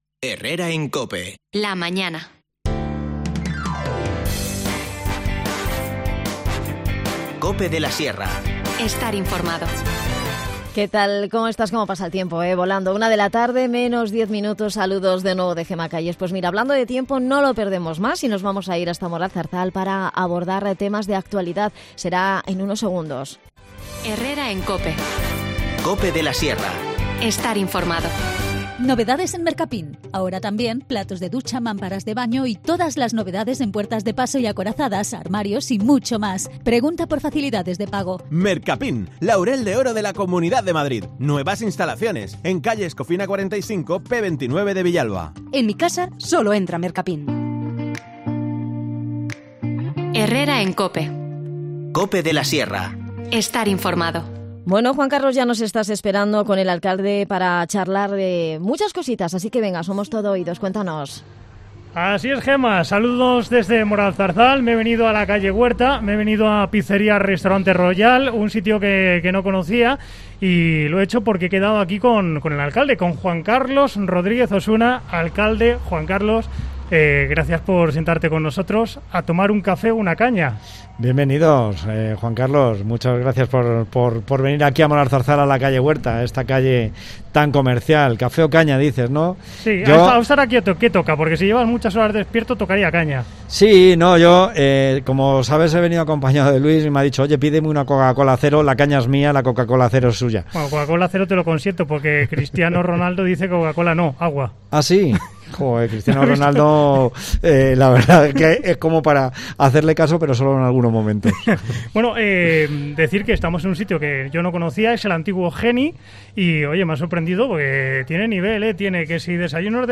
AUDIO: En 'Café o Caña' nos vamos hasta Moralzarzal para hablar con el alcalde, Juan Carlos Rodríguez Osuna, sobre temas de...